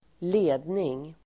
Uttal: [²l'e:dning]